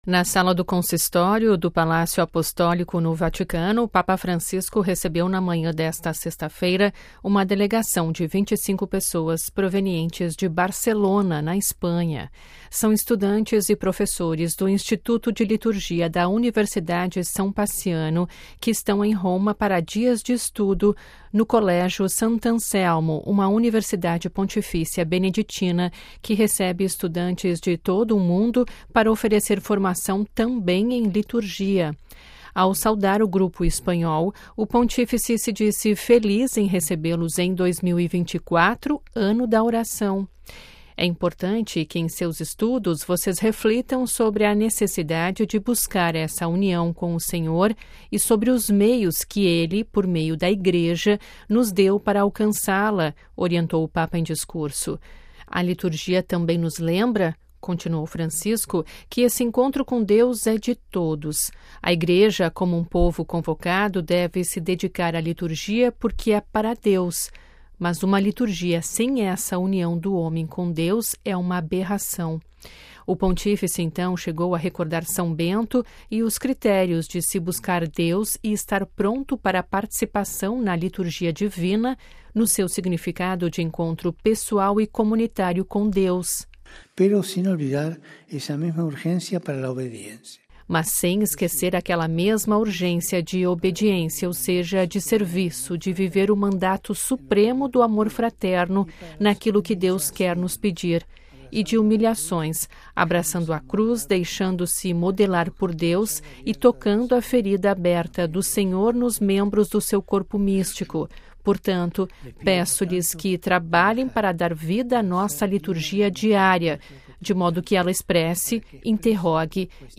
A audiência no Sala do Consistório, no Vaticano
Ouça a reportagem com a voz do Papa e compartilhe